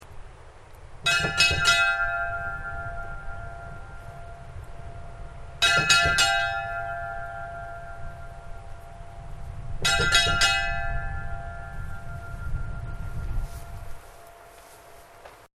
Í Gilsbakkakirkju eru tvær koparklukkur.
Við messu er báðum klukkum hringt nokkrum sinnum 3 slög.
gilsbakkakirkja_messa.mp3